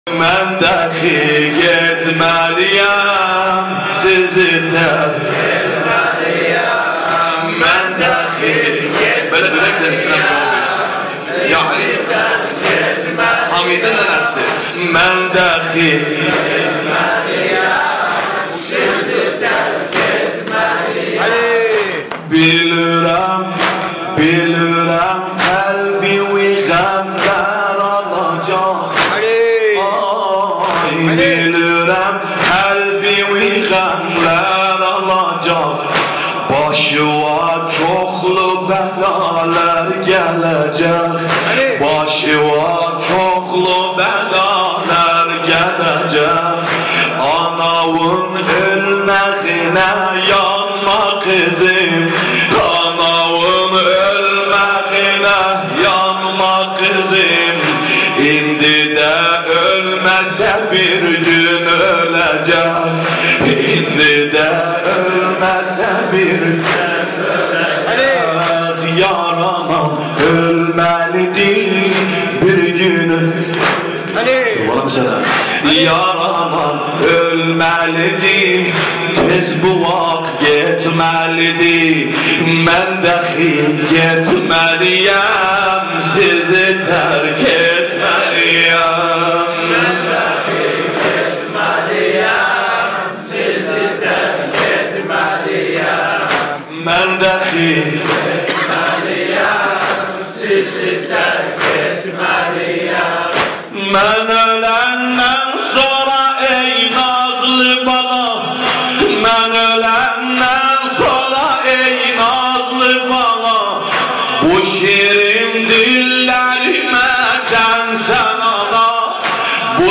متن مداحی